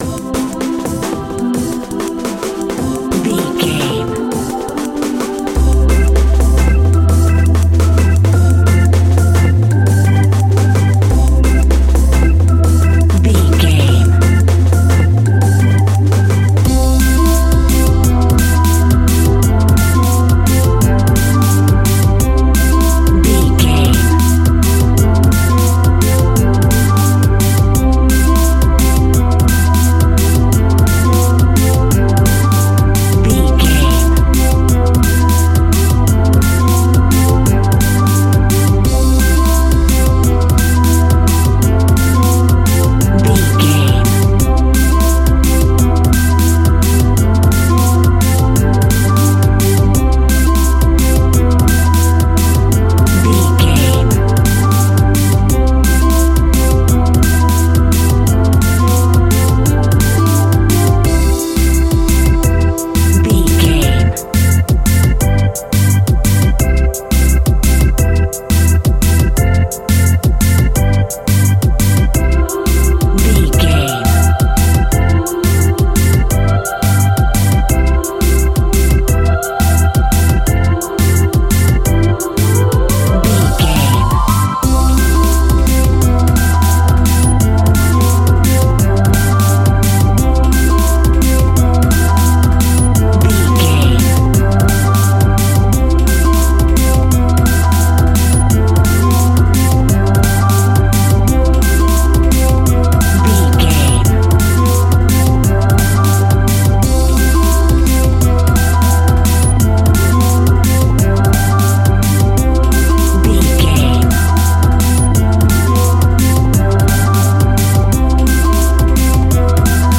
Ionian/Major
Fast
groovy
uplifting
lively
bouncy
bright
cheerful/happy
drum machine
synthesiser
electric piano
electric guitar
Drum and bass
electronic
synth bass
synth lead
synth drums
synth pad
robotic